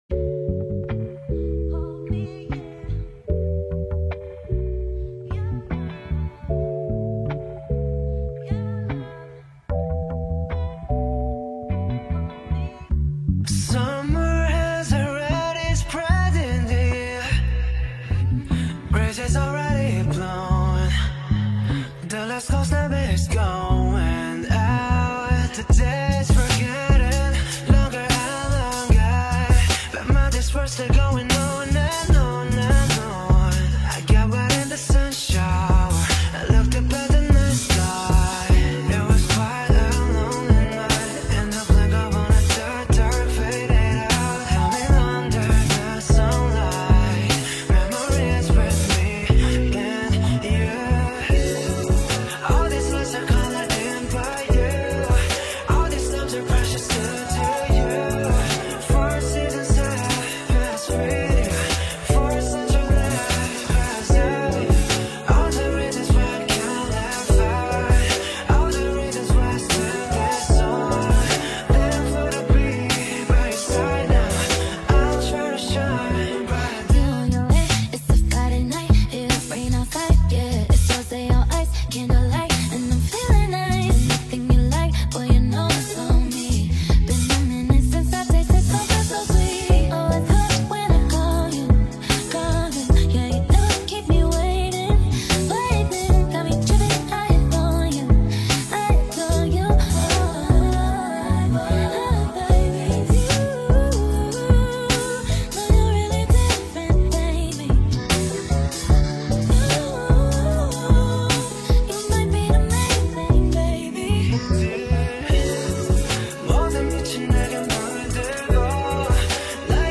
مشاپ موزیک